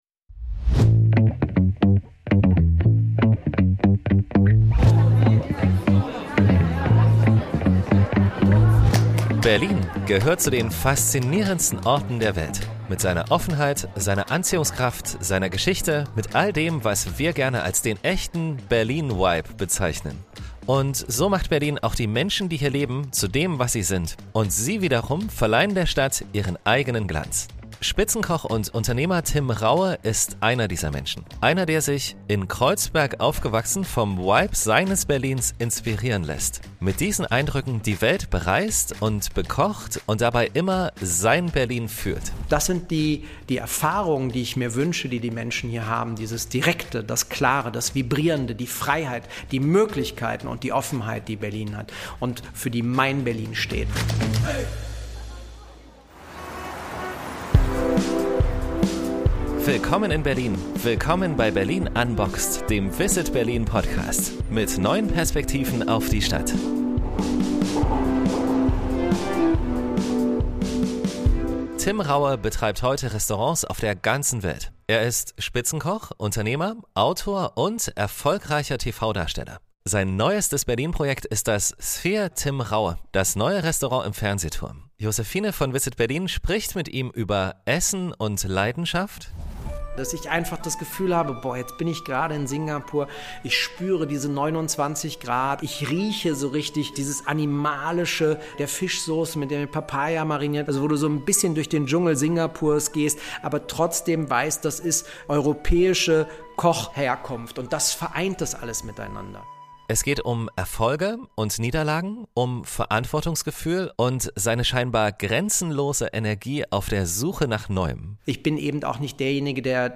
Im Interview erzählt Tim Raue über sich und die Inspiration für seine Rezepte. Er verrät uns seine Berliner Lieblings-Restaurants, was Berlin für ihn bedeutet, und was für ein Mensch hinter der Marke Tim Raue wirklich steckt.